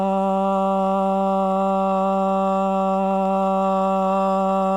It needs a signal that is a constant pitch like my voice going AHH.
You may need the attached file of my original AHH just to see the RECORD KWERK 7 working